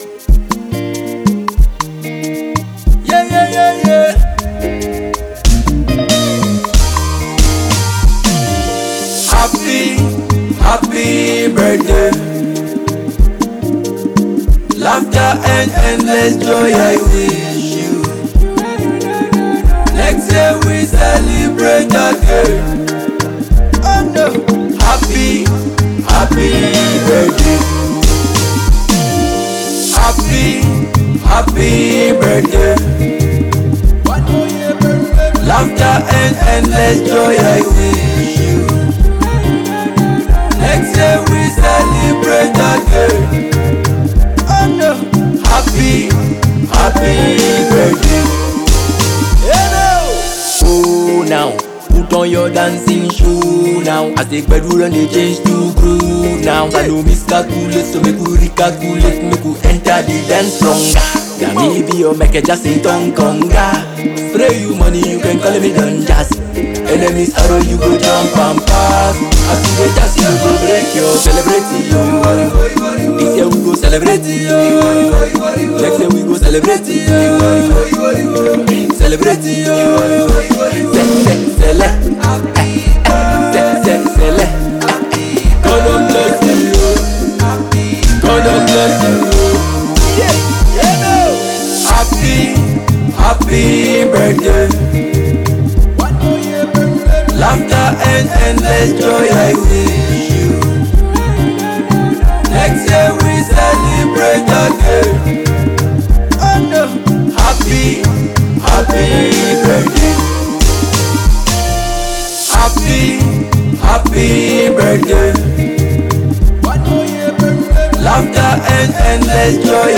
blending dancehall rhythms with gyration beats.